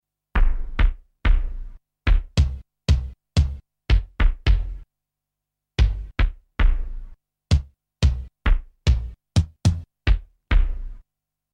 Play Kick Sound Effect - SoundBoardGuy
PLAY bass kick sound effect